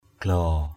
/ɡ͡ɣlɔ:/ 1.
glaow.mp3